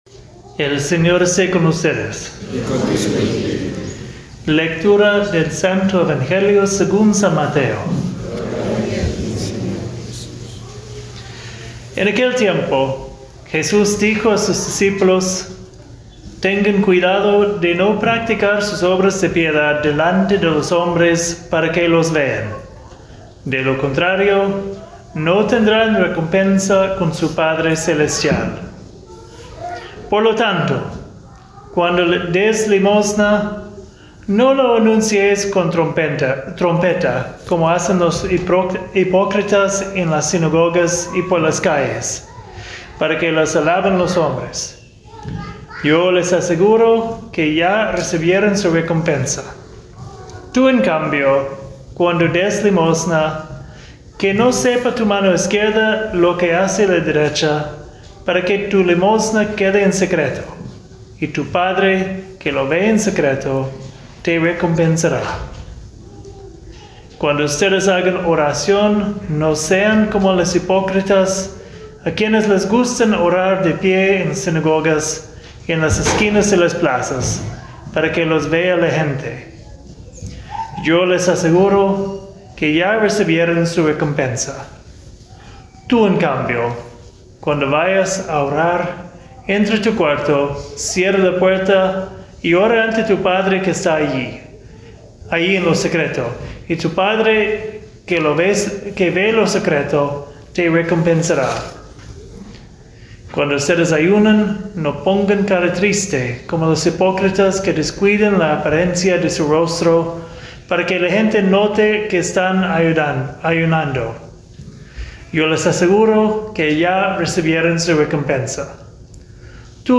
Spanish Homily